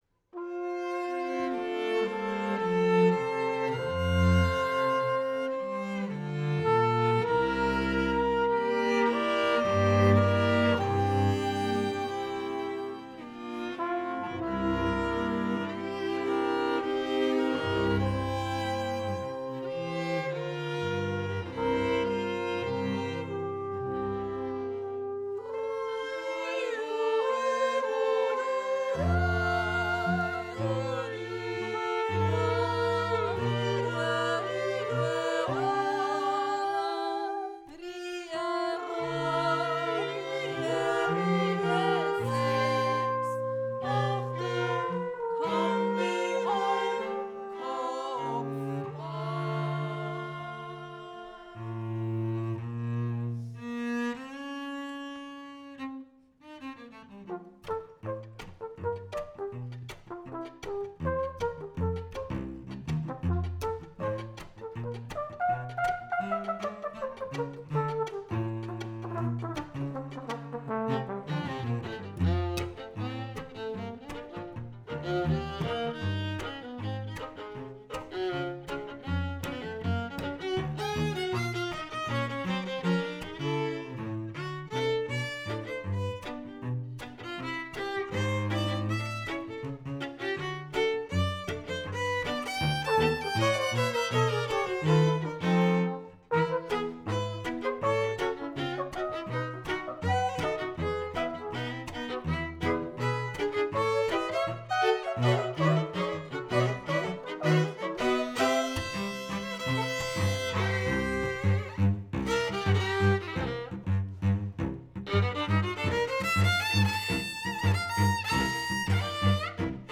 Violine und Gesang
Viola und Gesang
Cello und Gesang
Auf der Bühne ging mächtig die Post ab